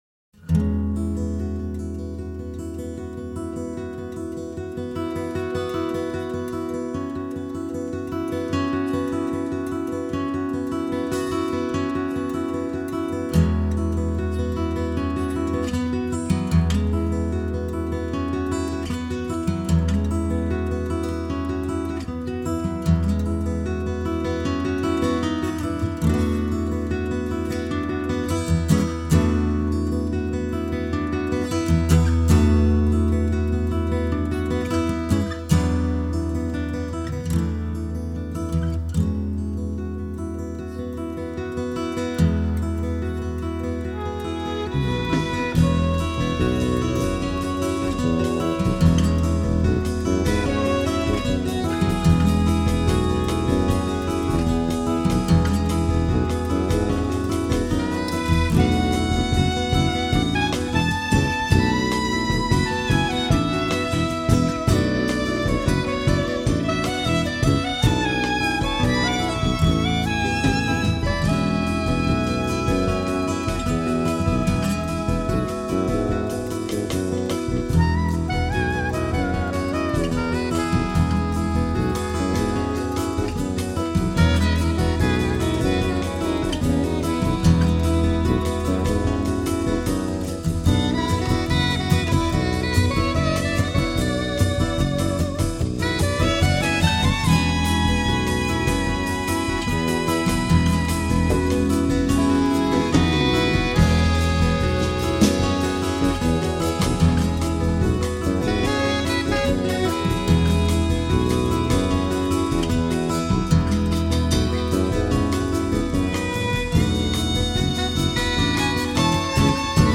Guitar, Composer
Violin
Soprano & Alto Sax
Electric Bass
Drums, Percussion